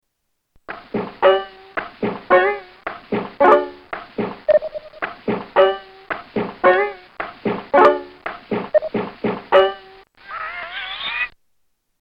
Crazy bango rhythm